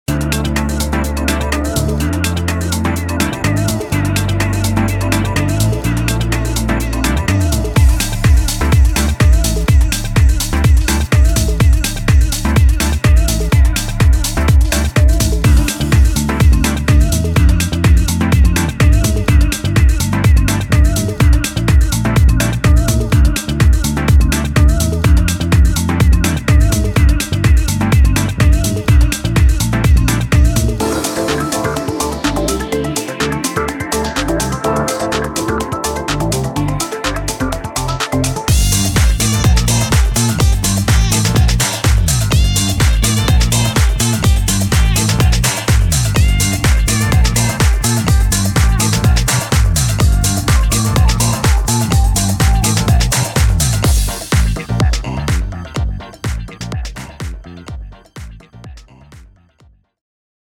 Deep House
Overflowing with a rich variety of instruments, including electric guitars, electric pianos, organs, clavinets, various keyboards, mallets, saxophones, strings, lead synthesizers, arpeggiated synthesizers, pads, and synthesized stabs—each contributing to a symphony of sonic possibilities. And to add that final flourish, both female and male vocal samples are also available for instant use. All loops harmoniously synchronize at a steady tempo of 125 BPM, a hallmark of house music and its diverse subgenres, including the captivating realm of deep house.
Soul Funk Deep House as a whole creates an enjoyable and lively assortment that showcases the enduring presence and relevance of funky house music.